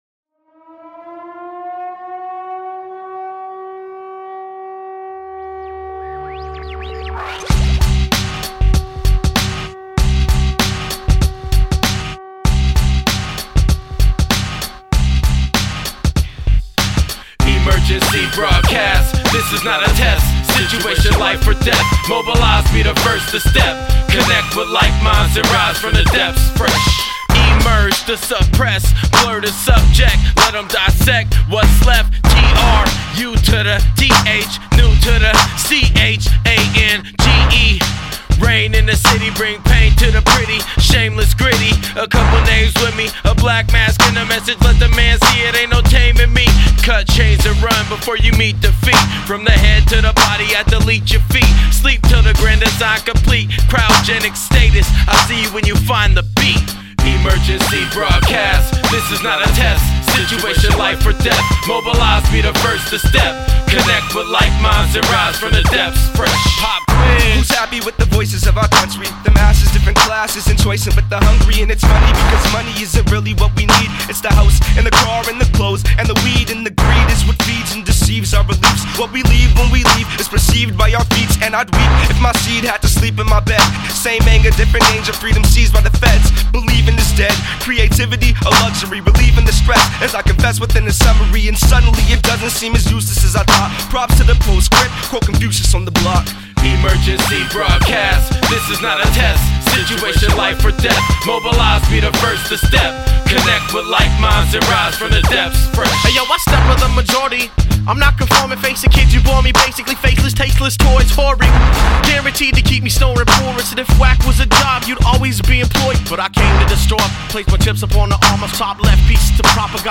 hip hop collective